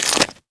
autocannon_grenade_pickup1.wav